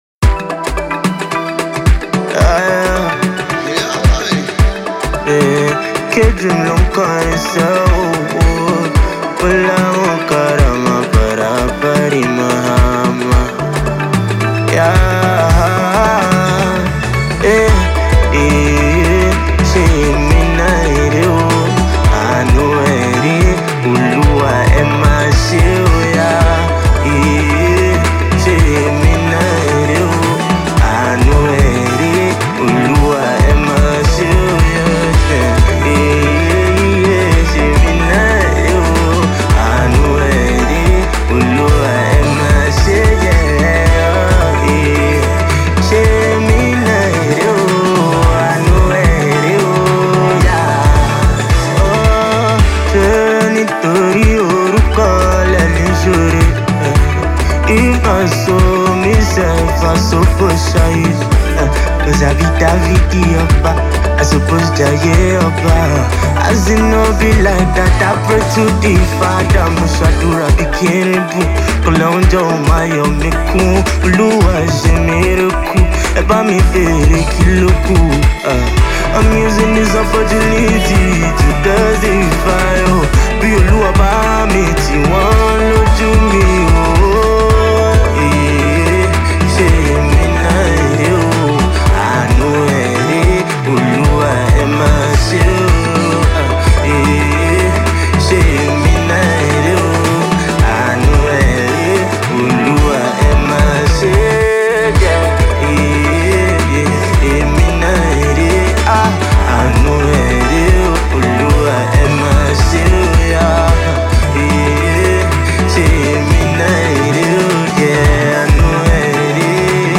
inspirational